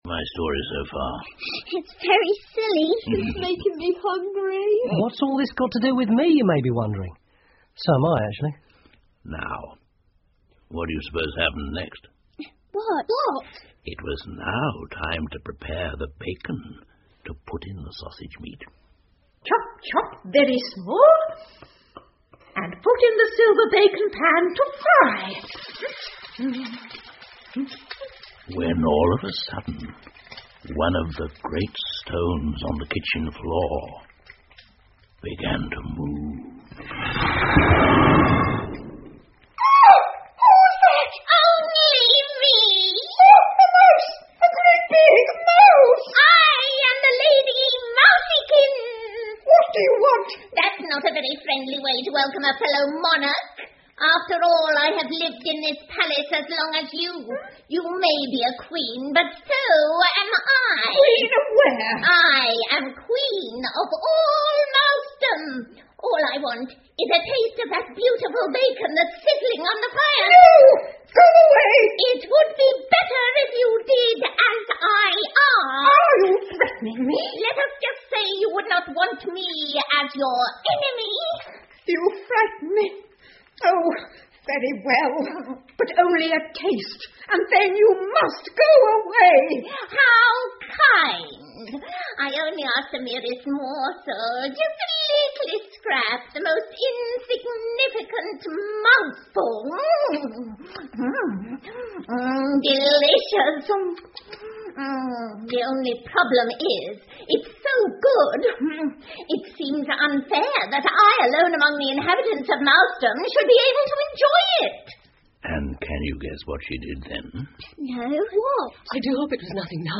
胡桃夹子和老鼠国王 The Nutcracker and the Mouse King 儿童广播剧 14 听力文件下载—在线英语听力室